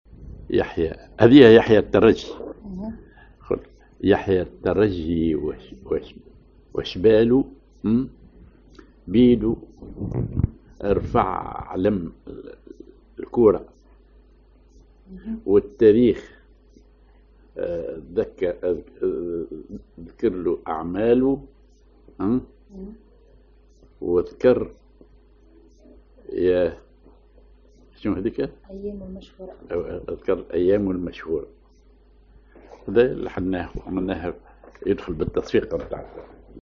Maqam ar يكاه
genre نشيد